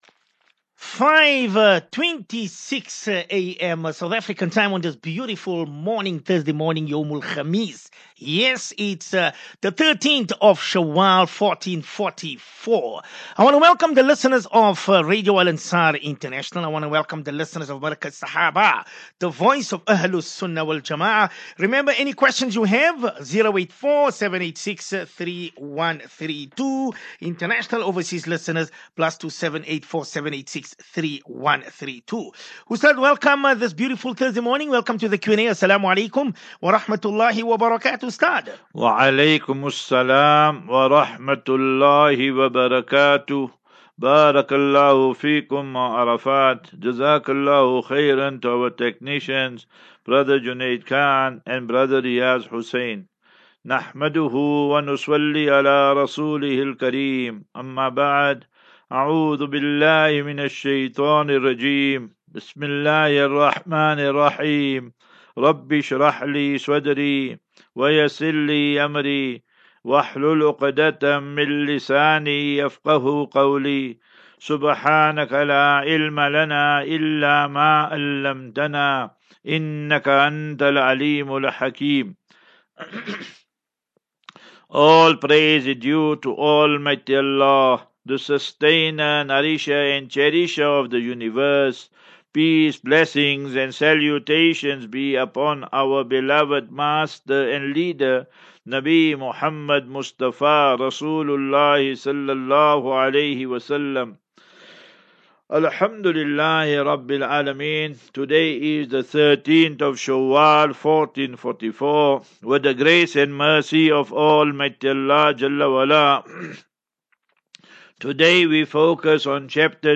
As Safinatu Ilal Jannah Naseeha and Q and A 4 May 04 May 23 Assafinatu